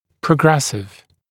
[prə’gresɪv][прэ’грэсив]прогрессирующий, нарастающий